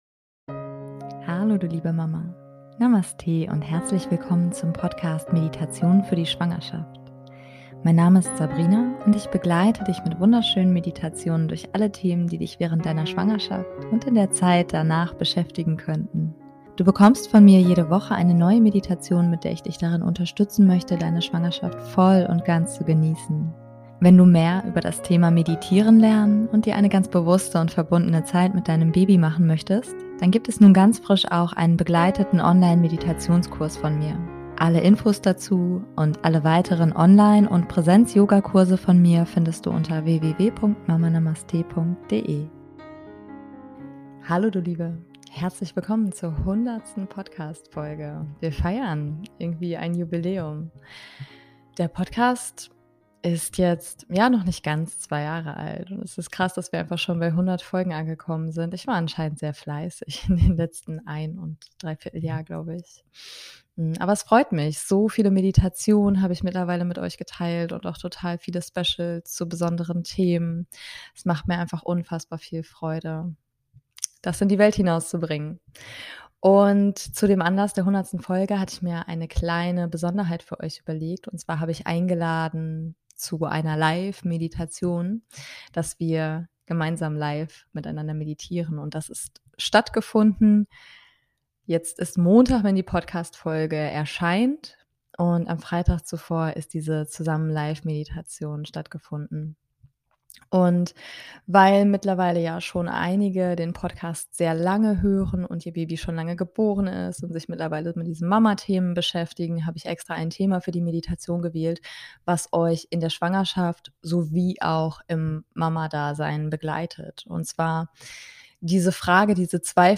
Egal, ob du schwanger bist oder dein Baby bereits geboren. Diese Meditation ist für dich.
Die 100. Folge haben wir mit einem Live Event gefeiert, so kannst du dir die Meditation auch mit Video auf Youtube anschauen und mir beim Anleiten zuschauen.